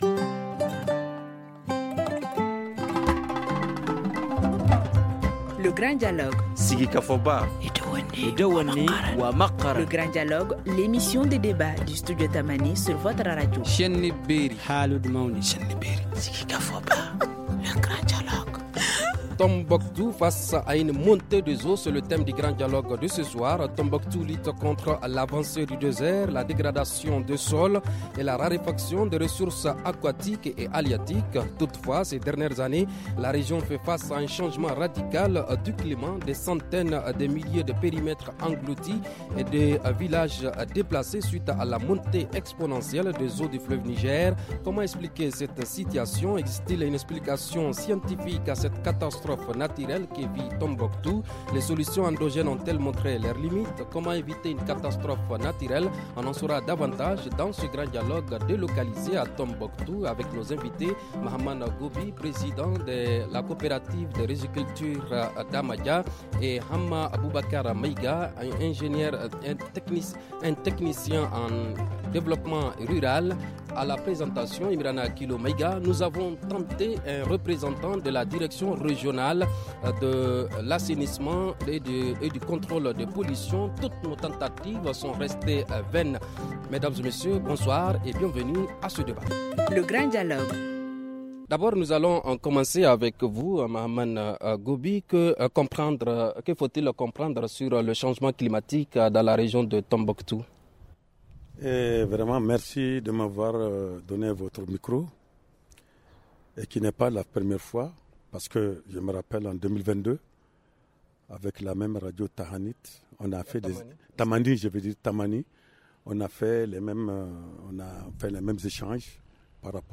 technicien en développement rural
On en saura davantage dans ce Grand Dialogue délocalisé à Tombouctou, la citée mystérieuse.